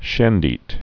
(shĕndēt)